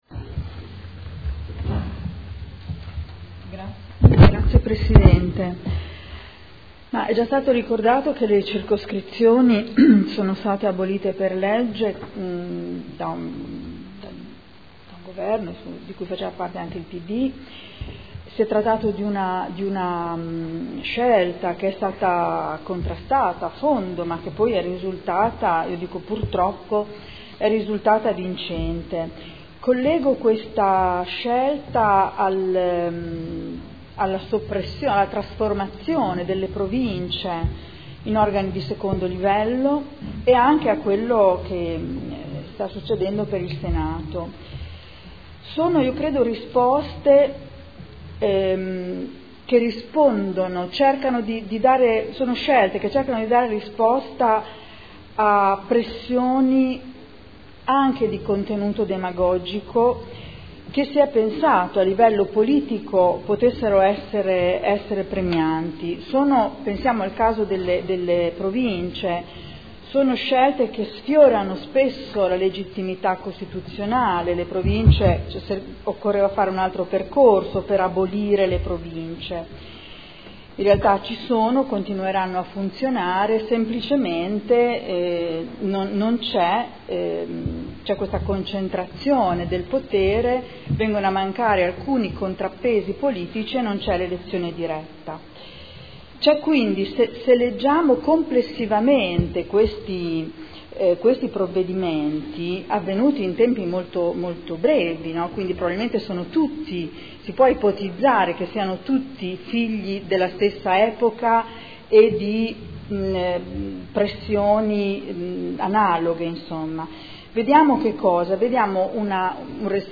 Seduta del 16 ottobre. Proposta di deliberazione: Individuazione e nomina dei componenti dei Consigli di Quartiere (Conferenza Capigruppo del 16 ottobre 2014).